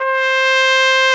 BRS CORNET06.wav